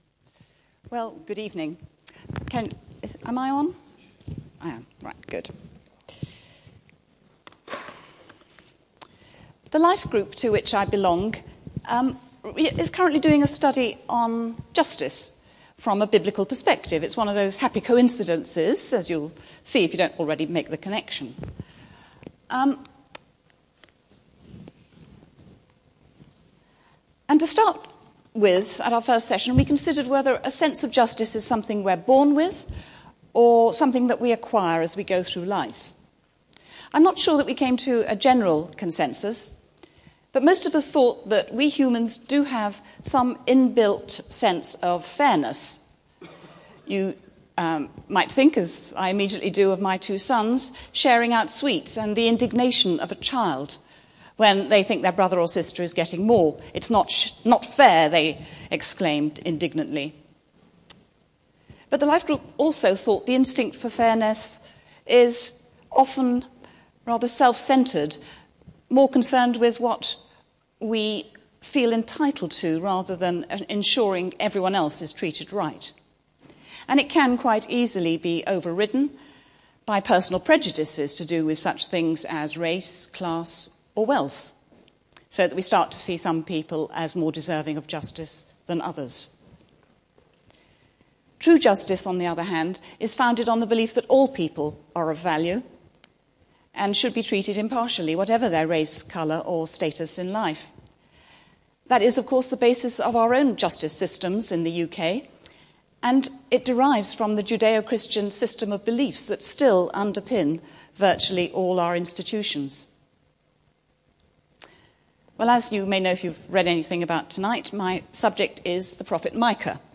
This sermon is part of a series